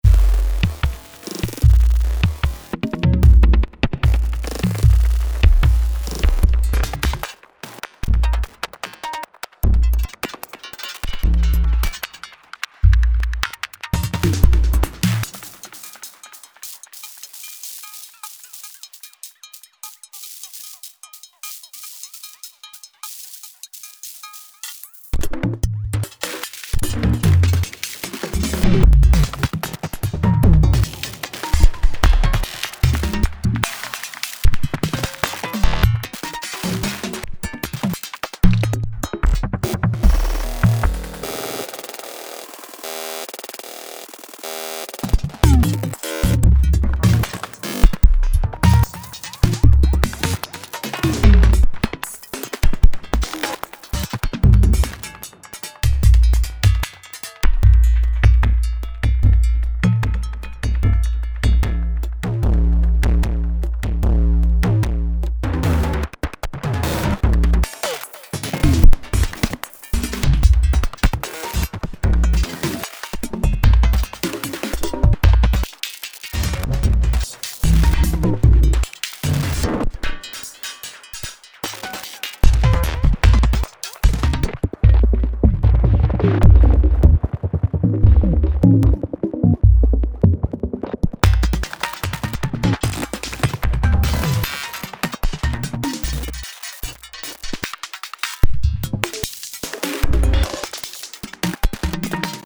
1 bar pattern, mostly PI machines, lfos on CTR AL machine.